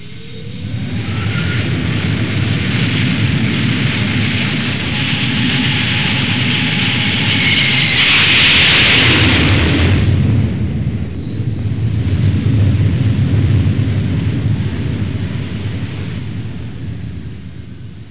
دانلود آهنگ طیاره 48 از افکت صوتی حمل و نقل
دانلود صدای طیاره 48 از ساعد نیوز با لینک مستقیم و کیفیت بالا
جلوه های صوتی